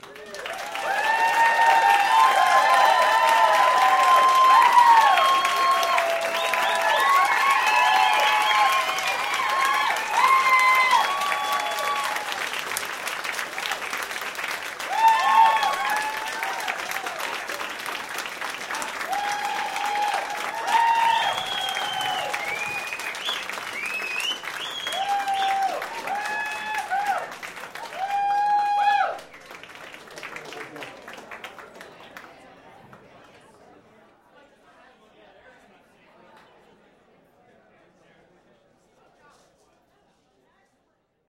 Здесь вы найдете фоновые шумы разговоров, звон бокалов, смех гостей и другие характерные звуки заведения.
Люди в баре хлопают, узнав о выходе певца